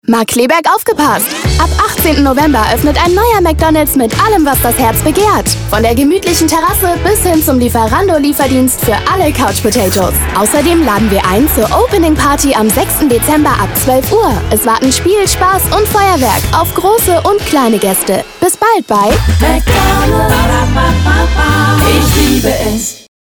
hell, fein, zart, plakativ
Jung (18-30)
Commercial (Werbung)